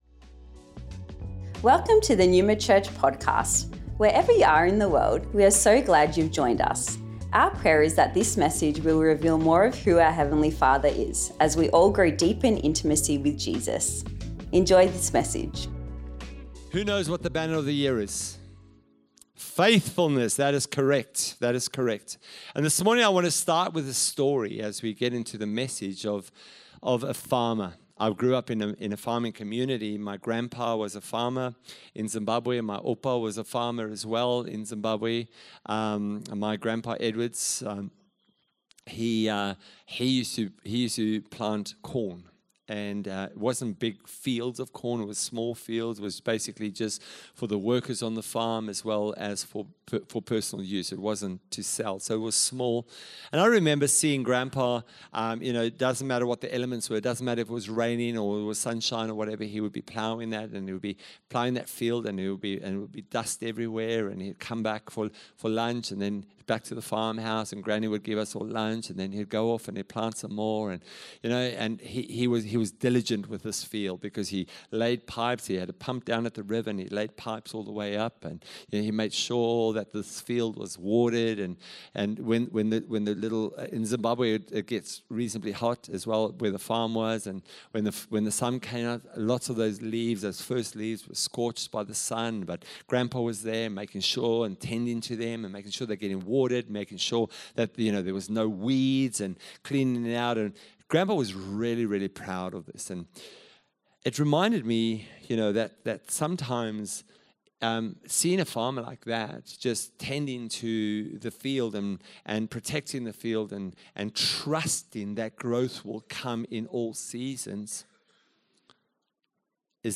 (**UPDATED - 11am sermon instead of 9am**)